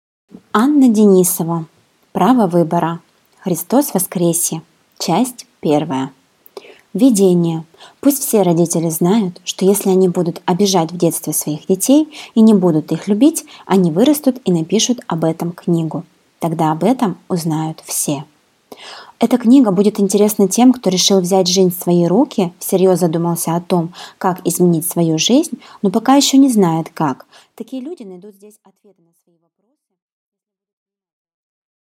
Аудиокнига Право выбора. Христос воскресе. Часть первая | Библиотека аудиокниг